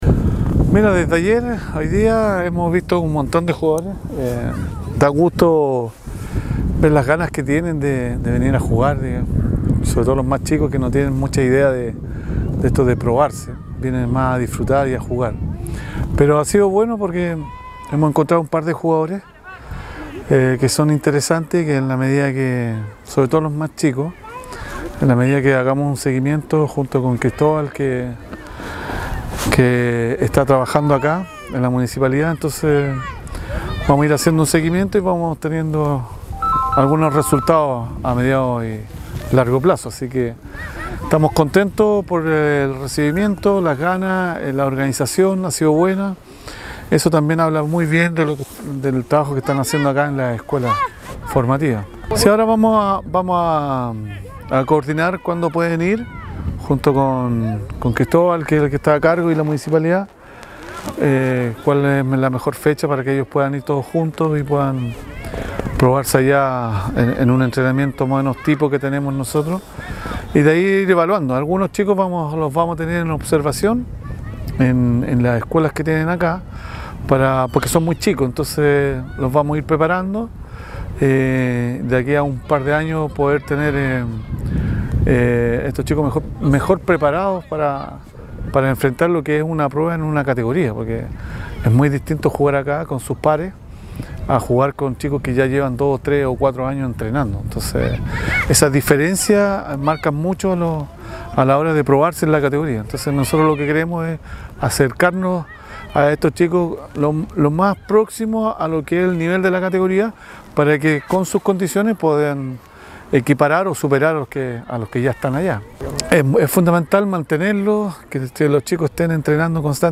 Cuña: Entrenador Formativo del club Universidad Católica – Mario Lepe
MARIO-LEPE-EX-FUTBOLISTA-PROFESIONAL-ACTUAL-ENTRENADOR-CATOLICA.mp3